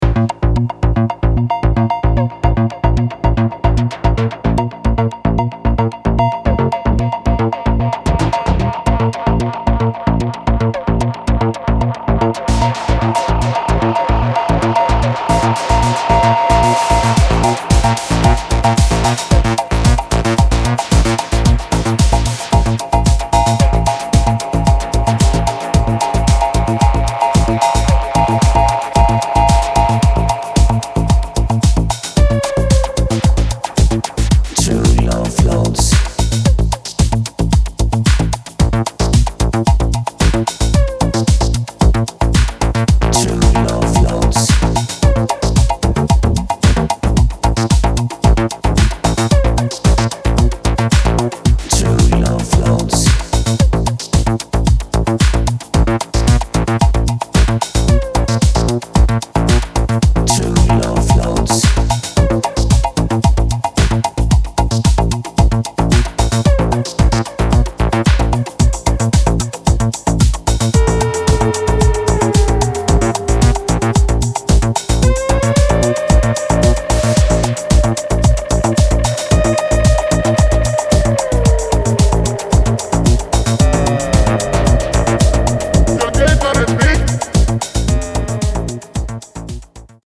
Styl: Electro, House, Techno